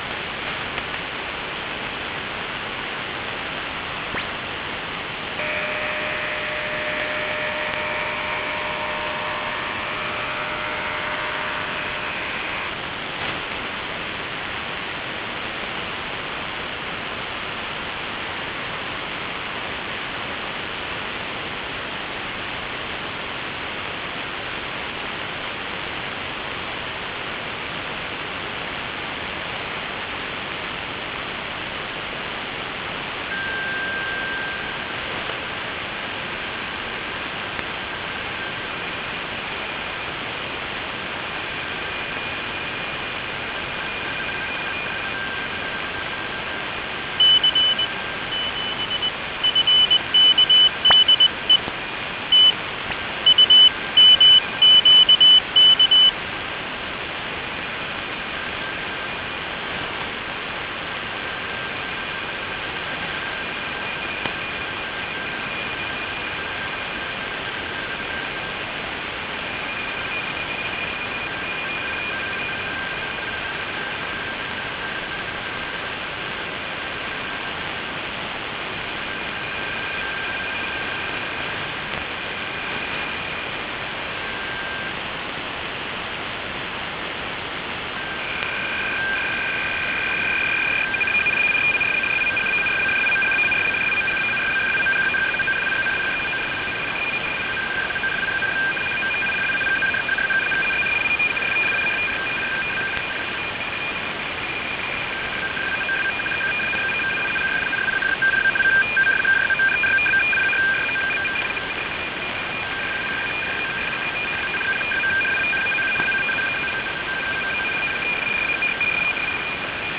QPSK-16 channels 105-75 & FSK-2 850-75 KG-84 Japanese Navy - принято 03.02.2017 в 20:57z на 5690 кГц